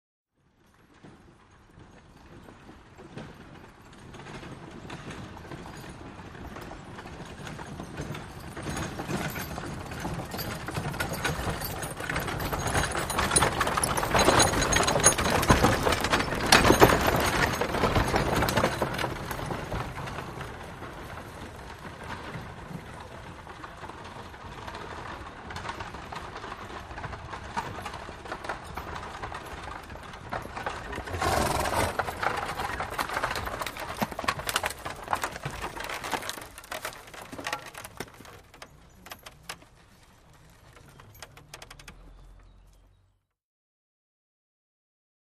2 Wagons; Ist By And Stop, 2nd Up And Stop, Mud And Stone Surface, R-l - Some Chatter In Left Channel From Driver After Ist Wagon Has Stopped